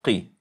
= tiden som behövs för att uttala قِ (Qi)